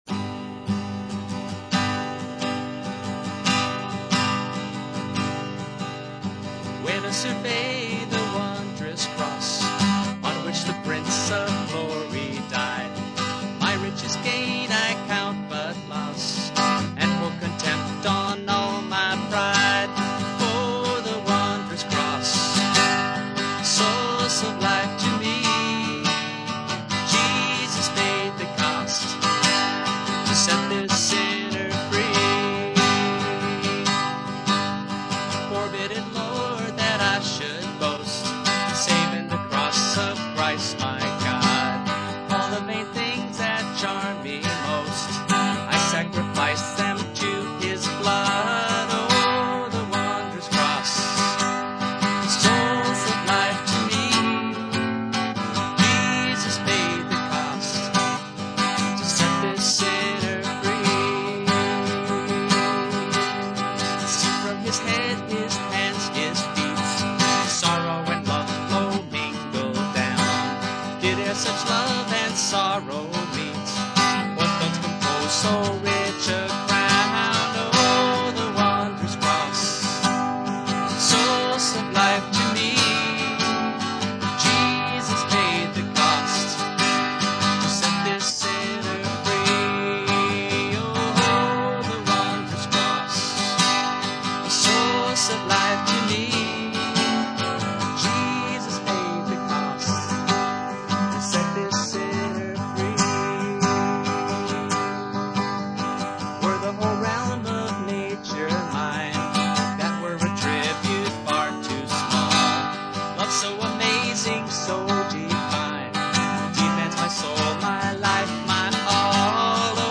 Calypso version of
in calypso style.